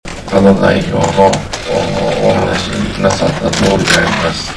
2012年12月28日、それらを説明する共同記者会見が行われた。